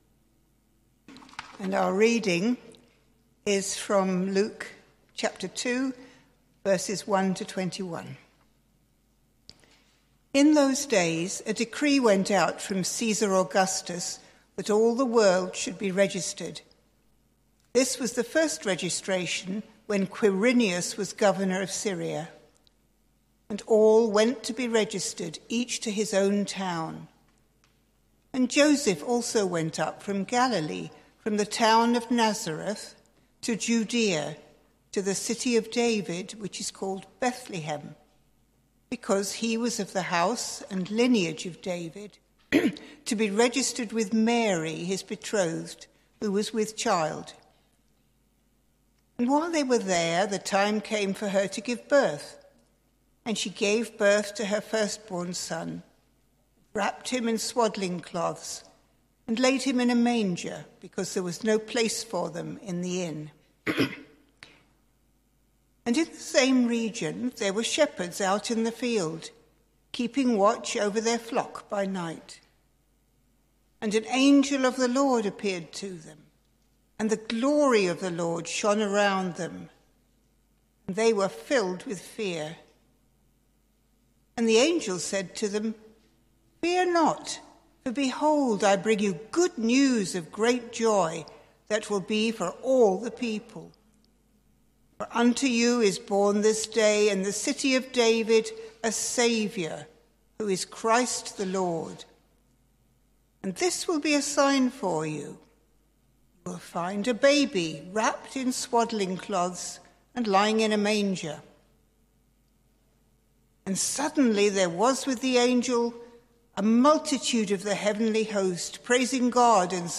Sermon Series: Luke’s Gospel